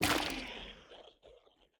Minecraft Version Minecraft Version 1.21.5 Latest Release | Latest Snapshot 1.21.5 / assets / minecraft / sounds / block / sculk / break7.ogg Compare With Compare With Latest Release | Latest Snapshot
break7.ogg